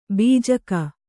♪ bījaka